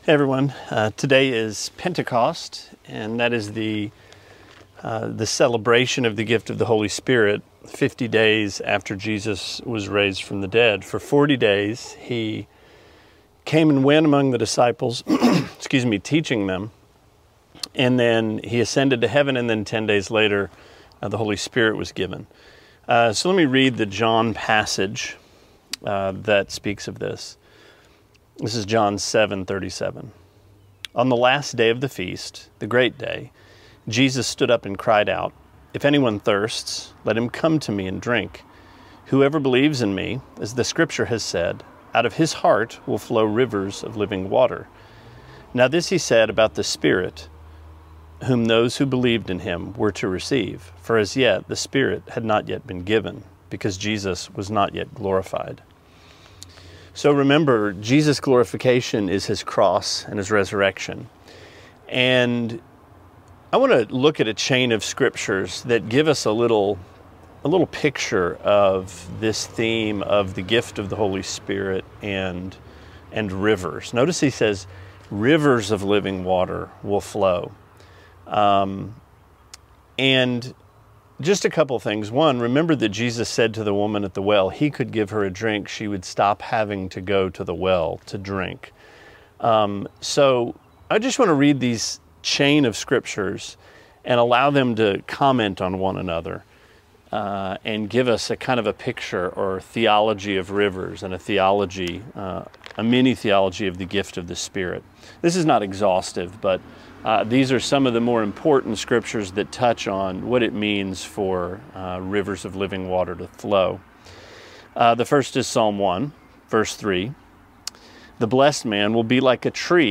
Sermonette 5/31: John 7:37-39: Living Water (Pentecost)